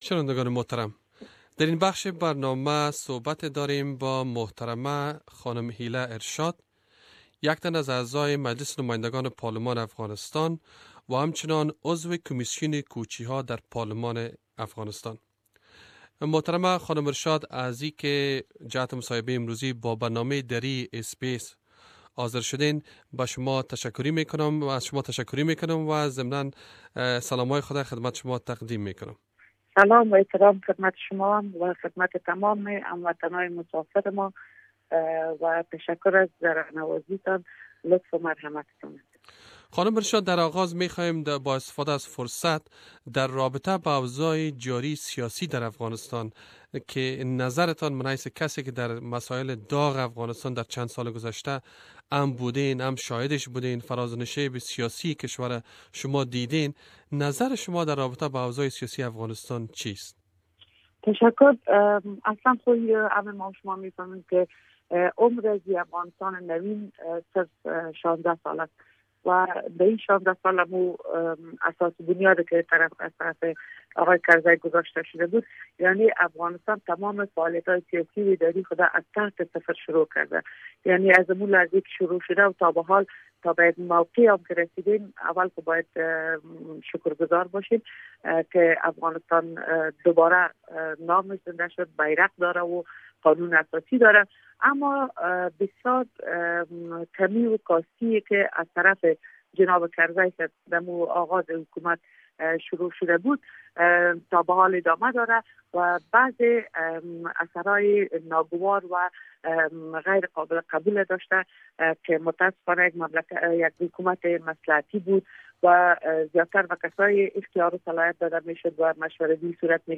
Interview with Hila Ershad Afghan MP